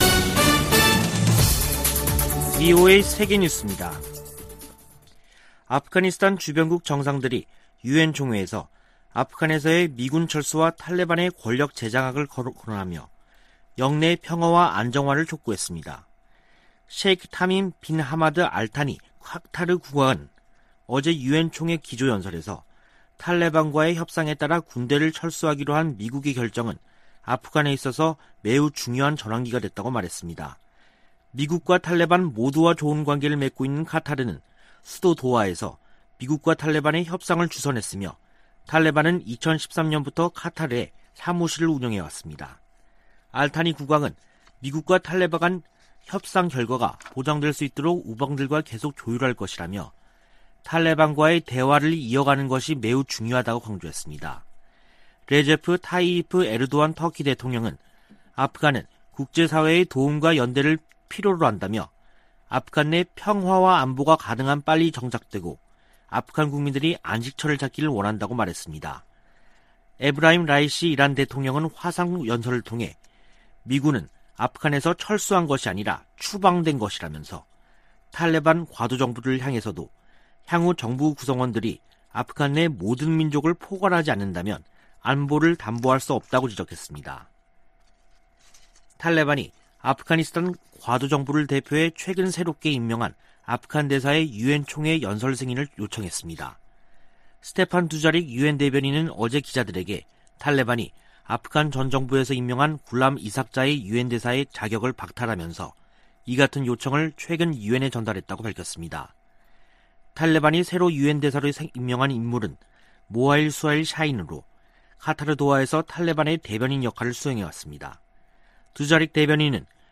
VOA 한국어 간판 뉴스 프로그램 '뉴스 투데이', 2021년 9월 22일 3부 방송입니다. 조 바이든 미국 대통령이 한반도 완전 비핵화를 위해 지속적 외교와 구체적 진전을 추구한다고 밝혔습니다. 문재인 한국 대통령은 종전선언을 제안했습니다. 미 연방수사국(FBI)이 북한의 사이버 역량 증대를 지적했습니다.